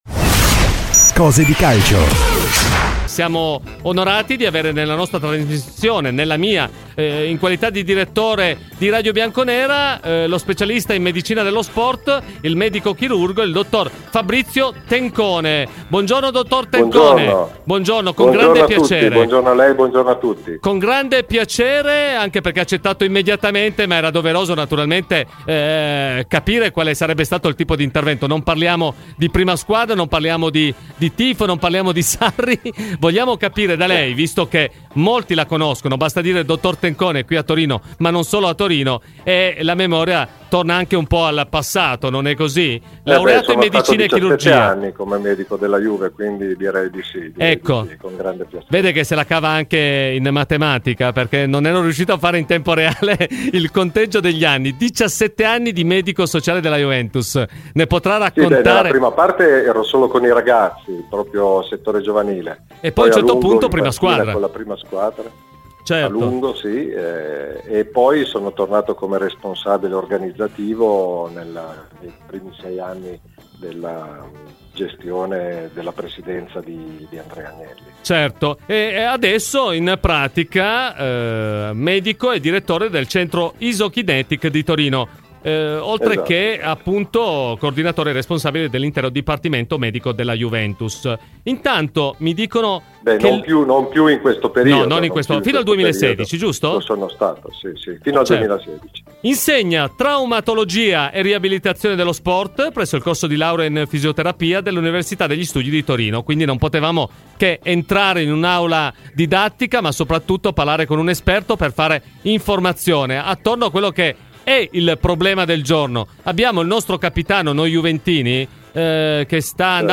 Ai microfoni di Radio Bianconera, nel corso di ‘Cose di Calcio’